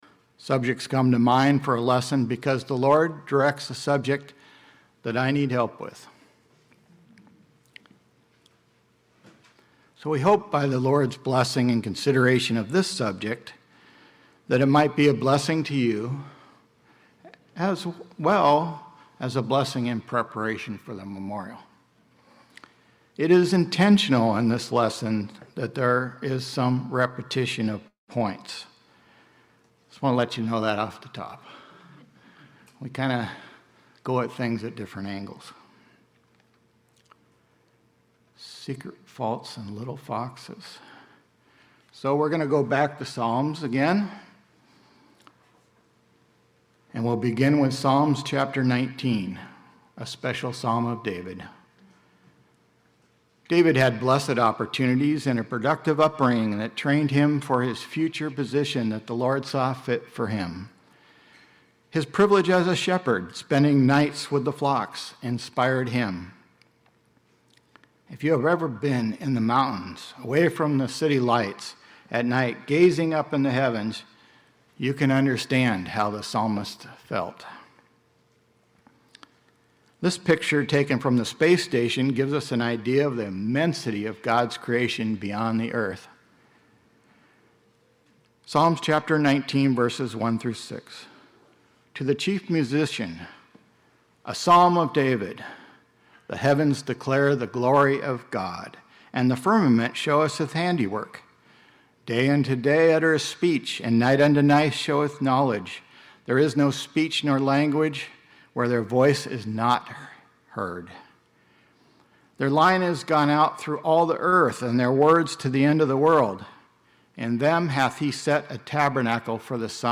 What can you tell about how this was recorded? Series: 2026 Wilmington Convention